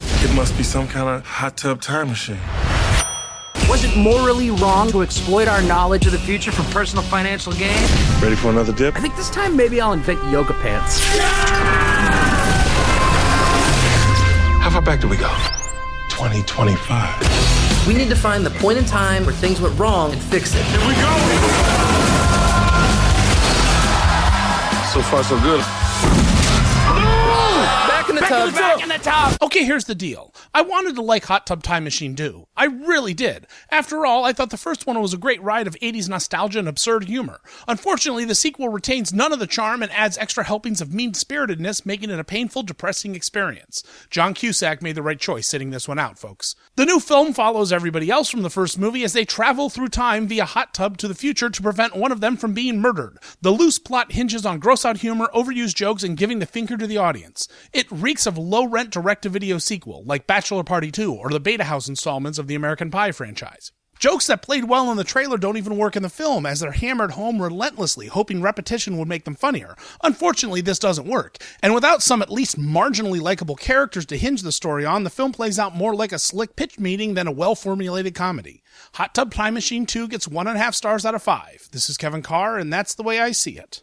‘Hot Tub Time Machine 2’ Movie Review